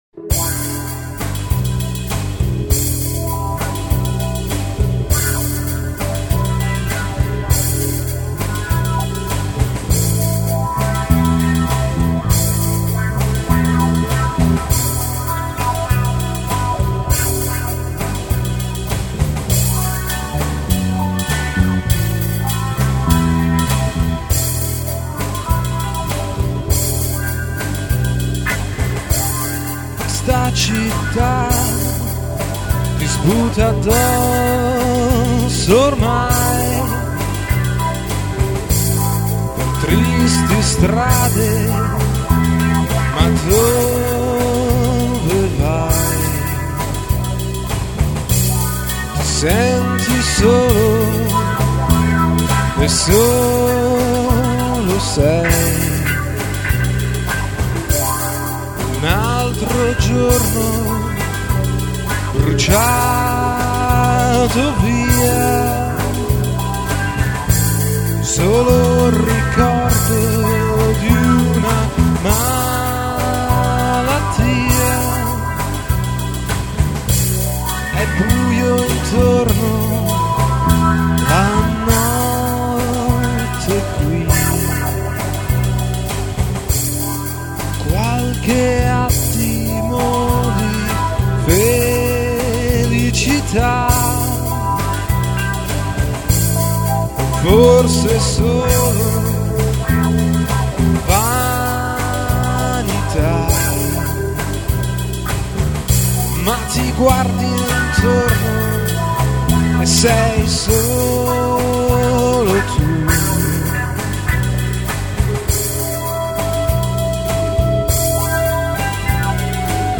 Chitarre, Basso e Programming